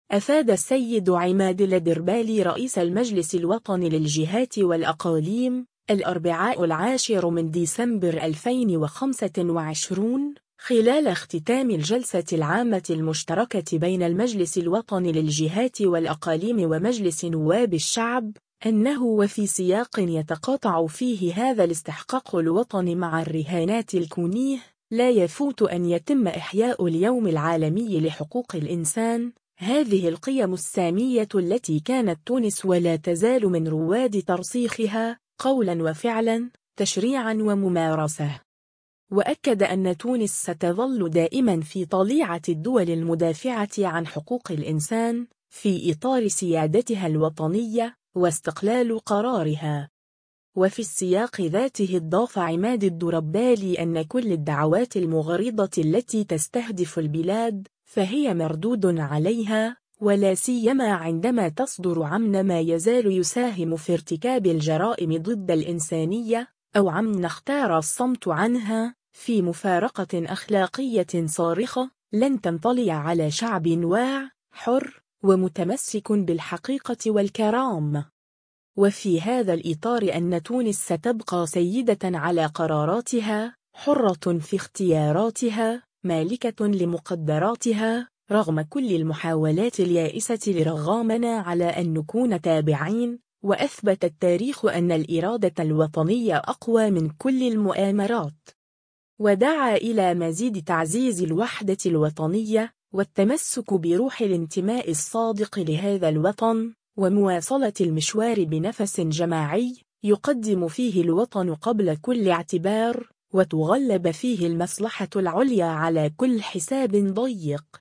أفاد السيد عماد الدربالي رئيس المجلس الوطني للجهات والأقاليم، الأربعاء 10 ديسمبر 2025، خلال اختتام الجلسة العامة المشتركة بين المجلس الوطني للجهات والأقاليم ومجلس نواب الشعب، “أنه وفي سياق يتقاطع فيه هذا الاستحقاق الوطني مع الرهانات الكونية، لا يفوت أن يتم إحياء اليوم العالمي لحقوق الإنسان، هذه القيم السامية التي كانت تونس ولا تزال من روّاد ترسيخها، قولًا وفعلًا، تشريعًا وممارسة”.